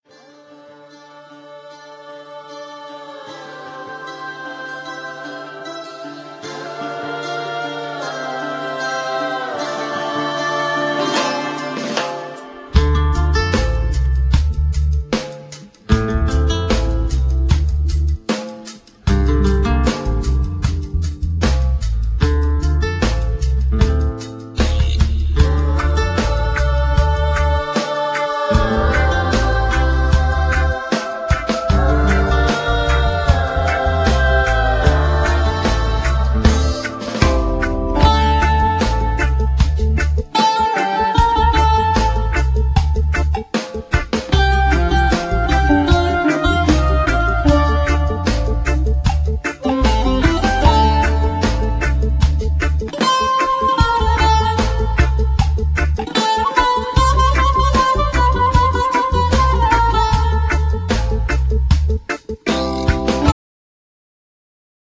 Genere : Pop Etno